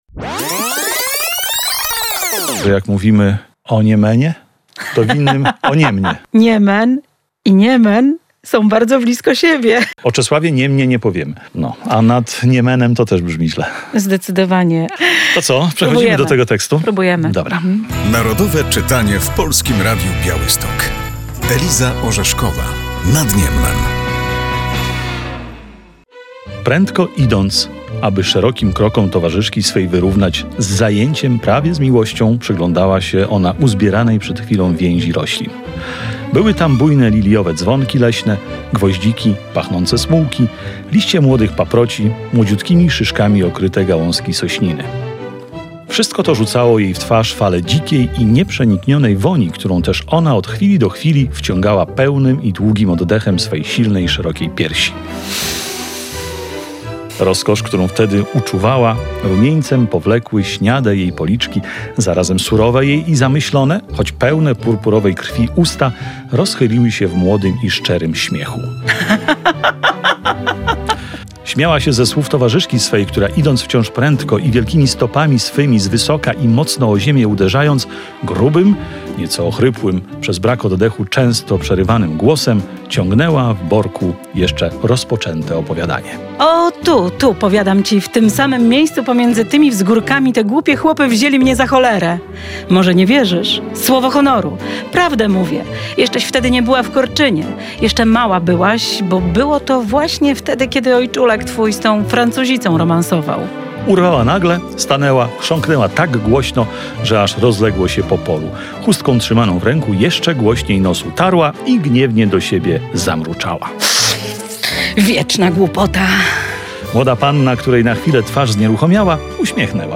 Pozytywistyczną powieść przybliża w tym roku ogólnopolska akcja Narodowe Czytanie. Włączają się do niej także dziennikarze Polskiego Radia Białystok.